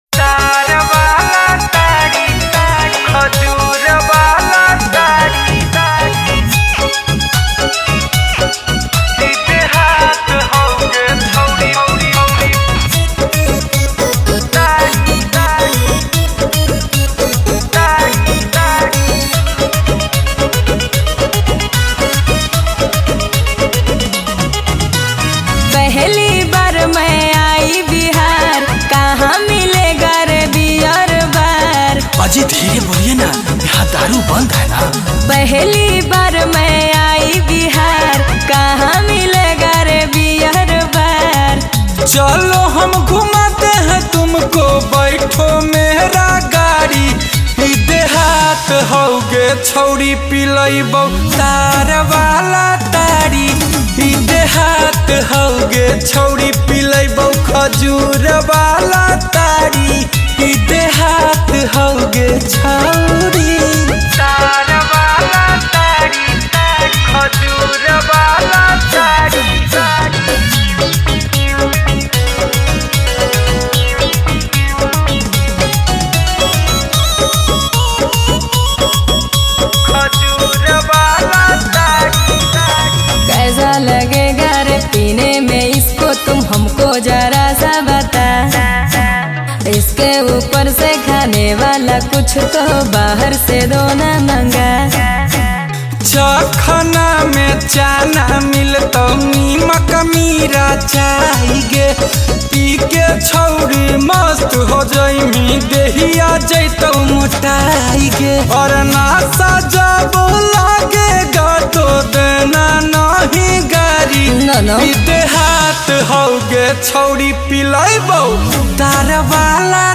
Bhojpuri